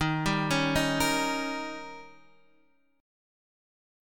Listen to D#7 strummed